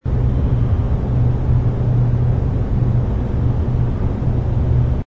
ambience_hangar_space.wav